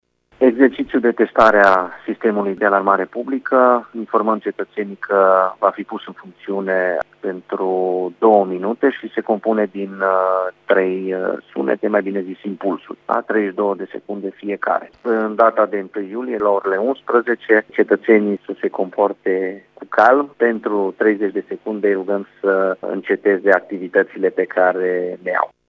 Sistemul va fi pus în funcțiune simultan cu sirenele aparţinând operatorilor economici din municipiu, a precizat viceprimarul Claudiu Maior: